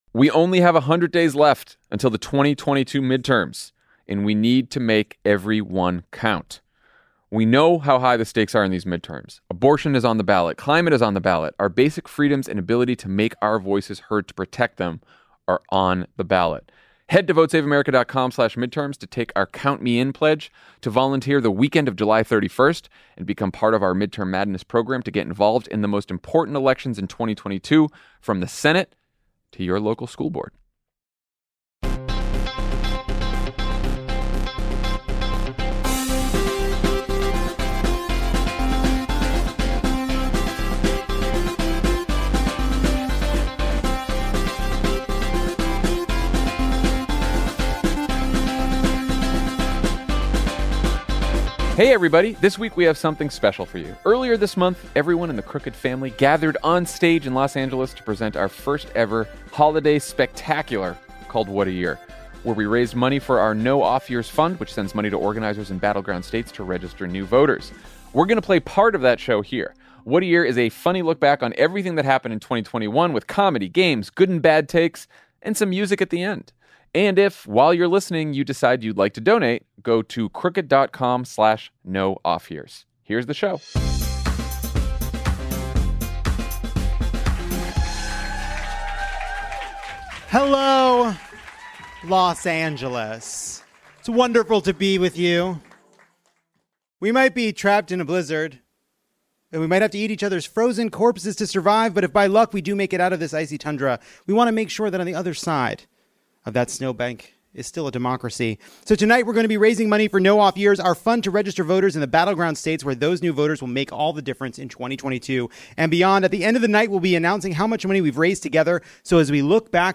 What A Year! (LIVE from LA)
Our first-ever holiday spectacular brings together everyone in the Crooked Media family to look back at the good, bad and absurd of 2021, with comedy, games and music.